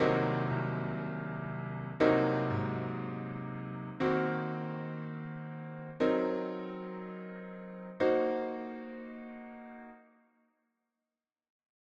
Lecciones de Piano
Acorde semidisminuido Cø half diminished variacion e inversiones
Posición cerrada y abierta de acordes en 5 compases.
Acorde-semidisminuido-Co-half-diminished-variacion-e-inversiones.ogg